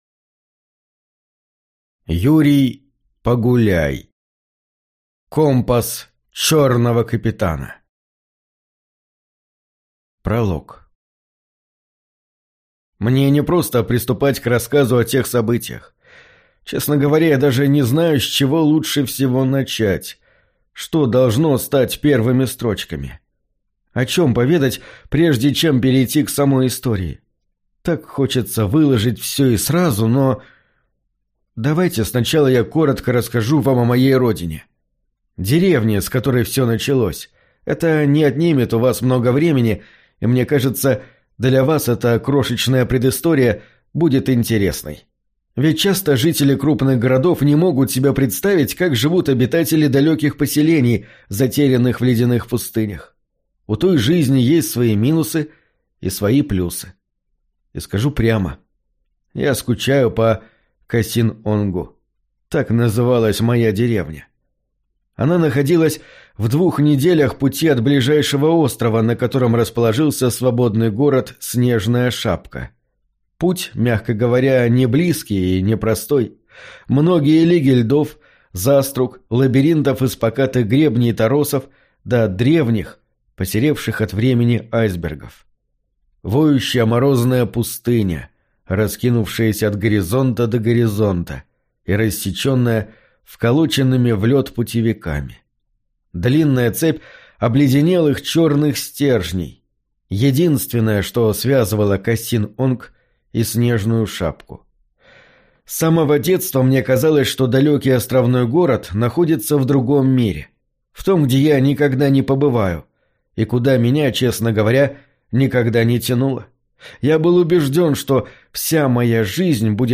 Аудиокнига Компас черного капитана | Библиотека аудиокниг